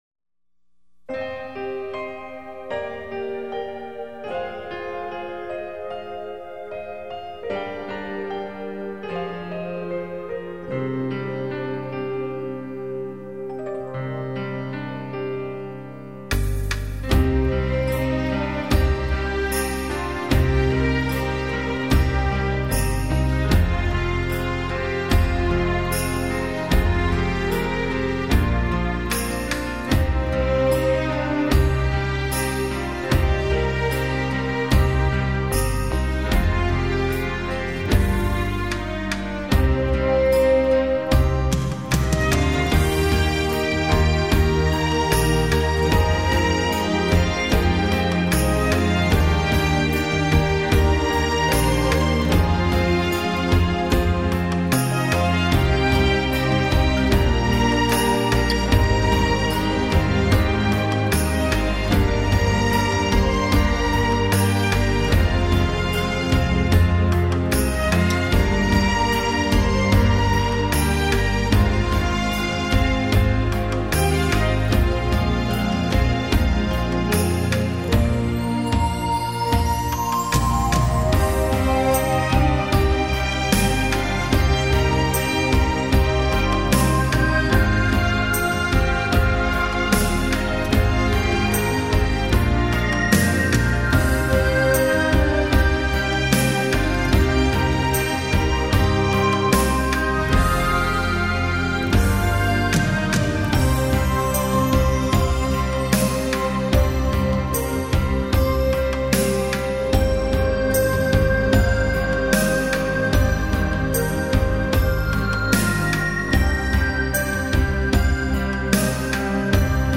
其實這些曲目...是早期霹靂跟天宇所用的人物場景配樂，不過事實上...這些是用很多動畫或是一些其他音樂集改編而來的，